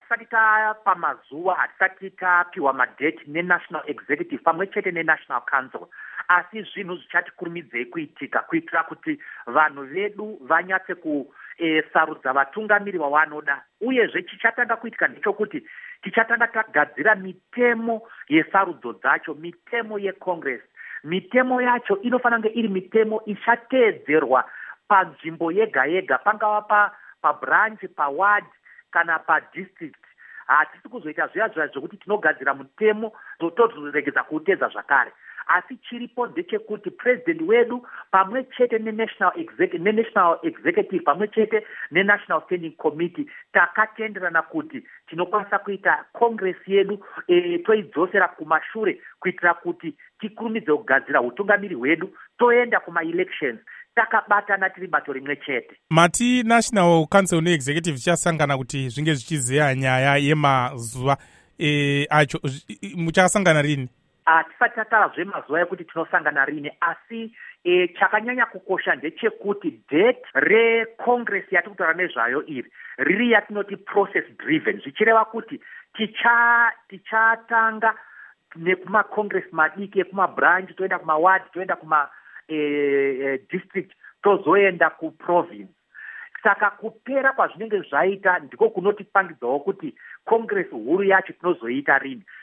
Hurukuro naVaDouglas Mwonzora